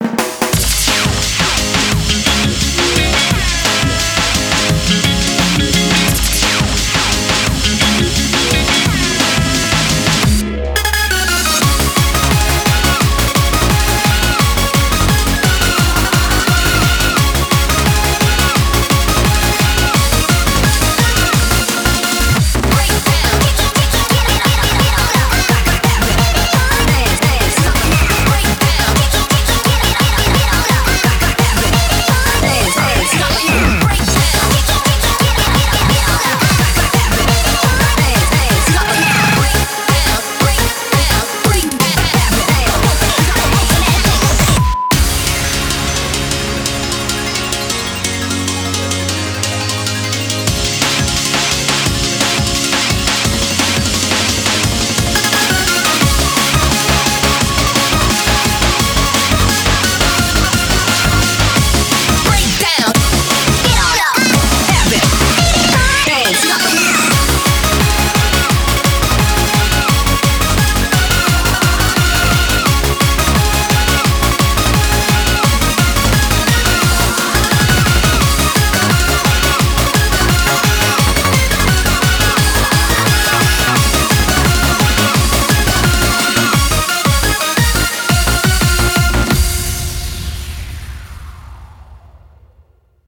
BPM173
Audio QualityLine Out